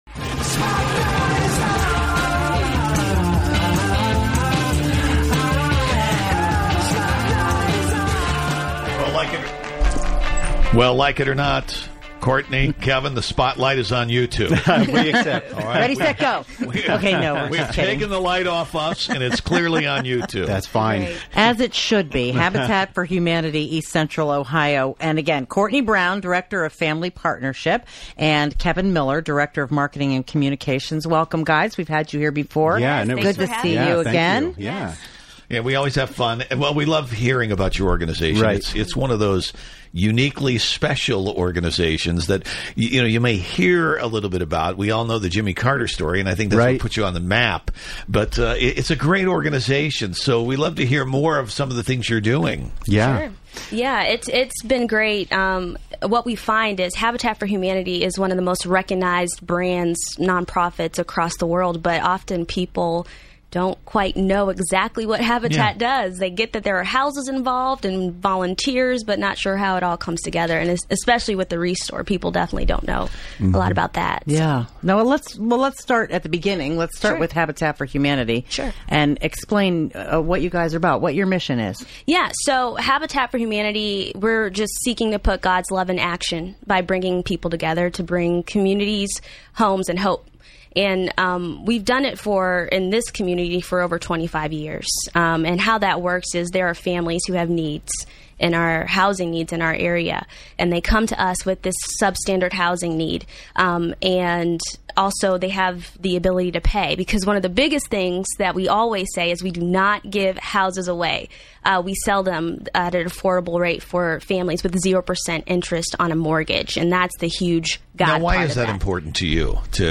We’re broadcasting the mission of Habitat for Humanity and Habitat for Humanity ReStore over the radio airwaves!